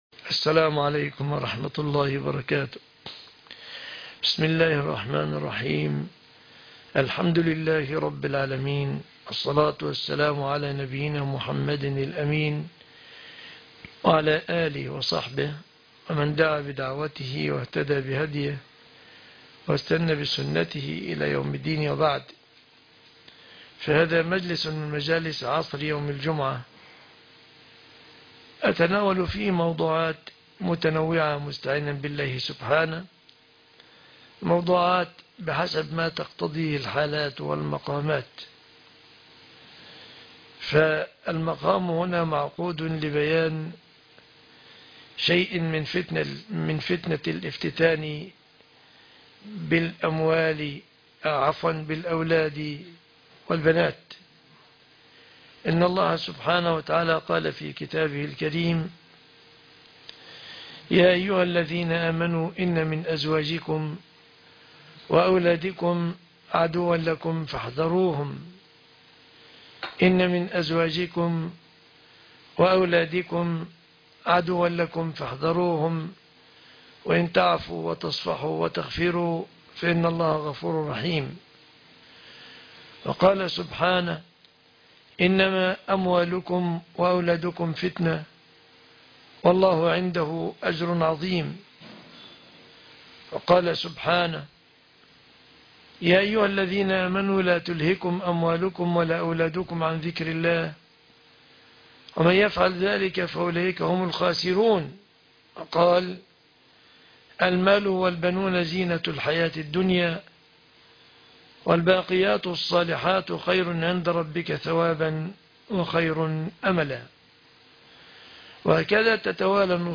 درس عصر الجمعة